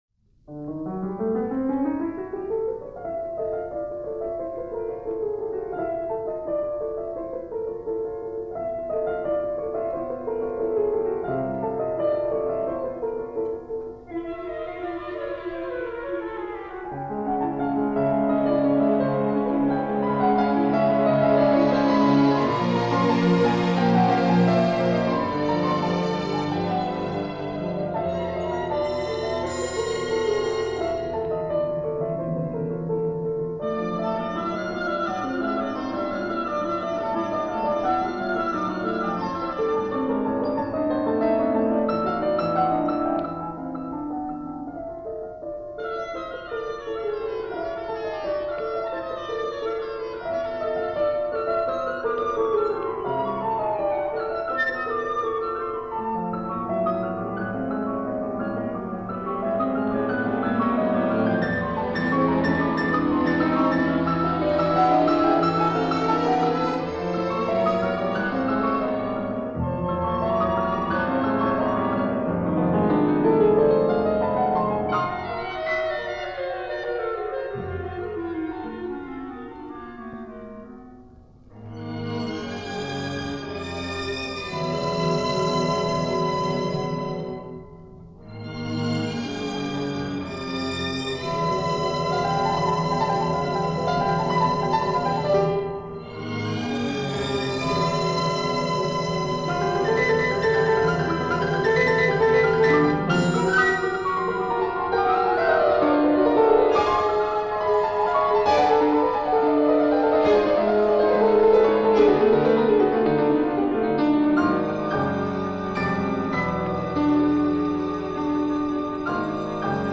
from a radio broadcast in Paris in 1965
pianist
in a broadcast from around 1965